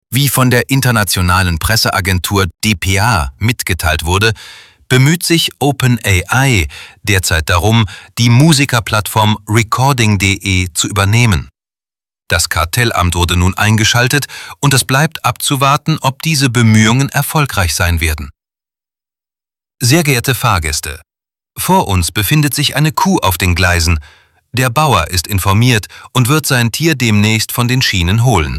Die Sprachausgabe ist hier auch echt gut.
Was ich interessant fand: Der zweite Text, die Durchsage über die Kuh, wird trotzdem sofort als falsch erkannt, weil Stimme und Sprechweise zu einer Nachricht passen, aber nicht zu solchen Durchsagen.